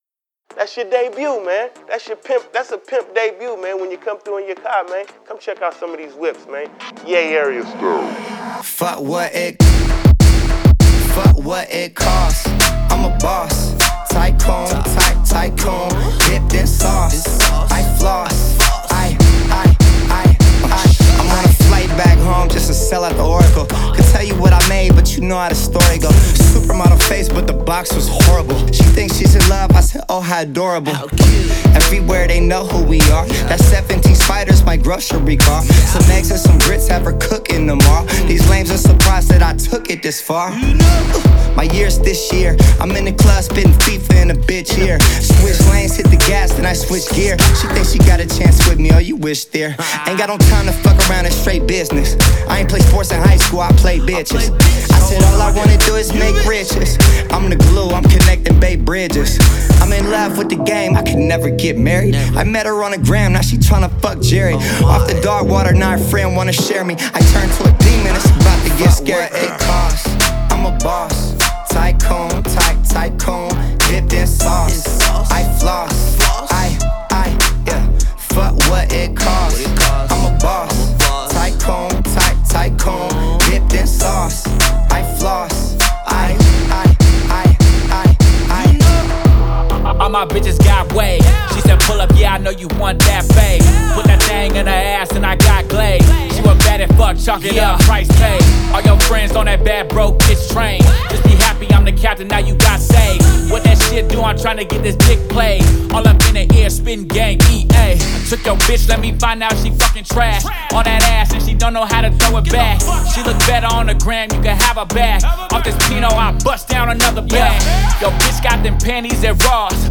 энергичная хип-хоп композиция